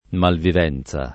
malvivenza [ malviv $ n Z a ] s. f.